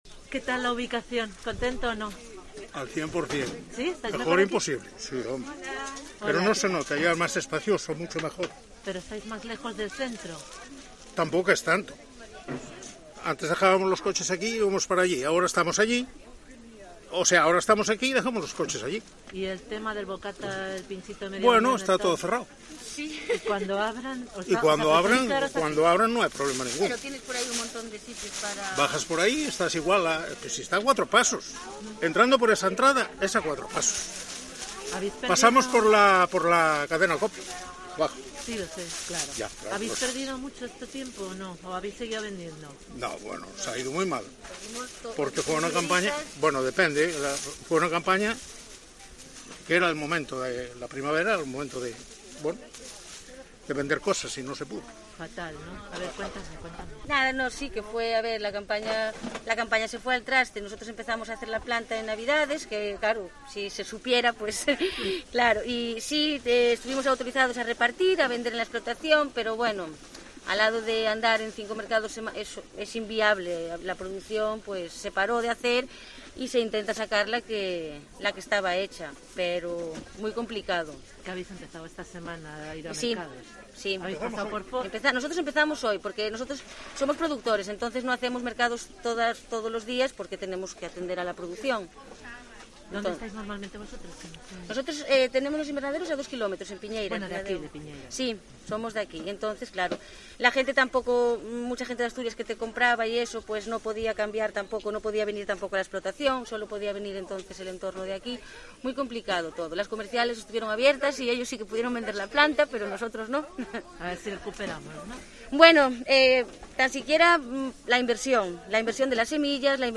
Declaraciones de los VENDEDORES DEL MERCADO LOCAL de Ribadeo